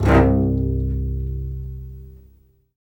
Index of /90_sSampleCDs/Roland LCDP13 String Sections/STR_Vcs Marc&Piz/STR_Vcs Odd Marc